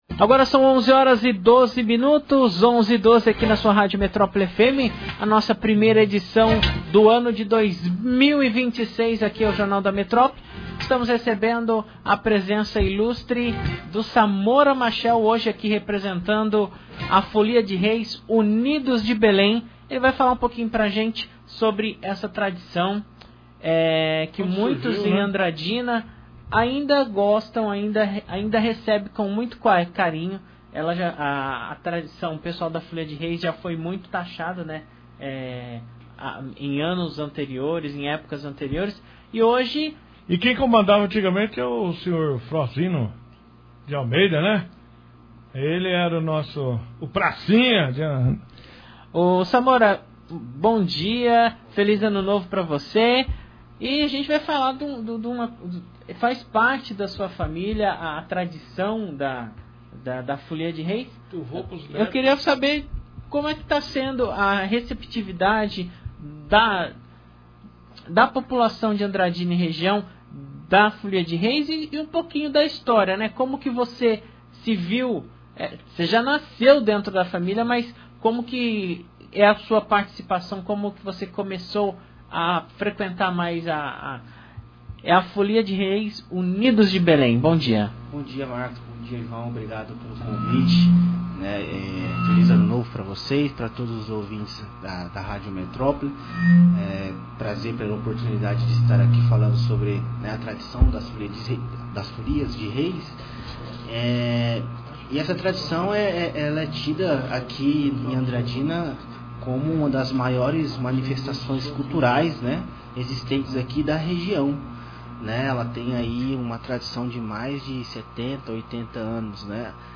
ENTREVISTA-FOLIA-DE-REIS-0201.mp3